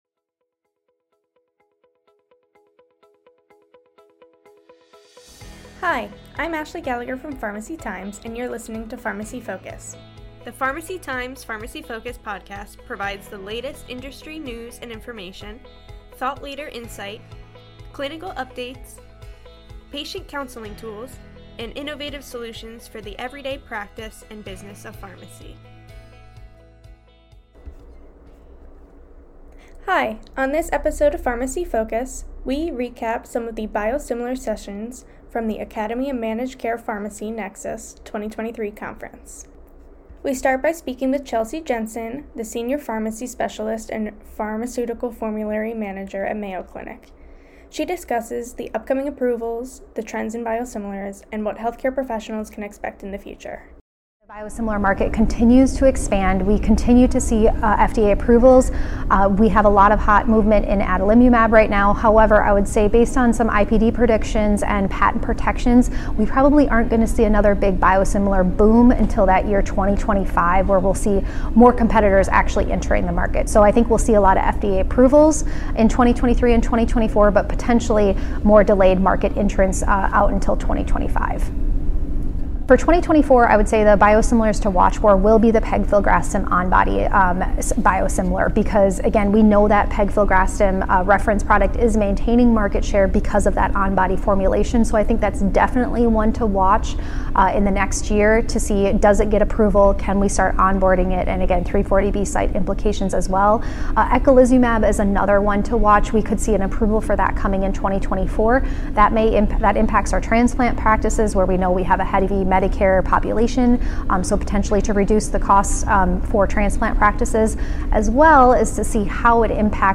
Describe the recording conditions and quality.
On this month's episode, Pharmacy Times covers the Academy of Managed Care Pharmacy Nexus 2023 conference, highlighting the updates on biosimilars. Interviews include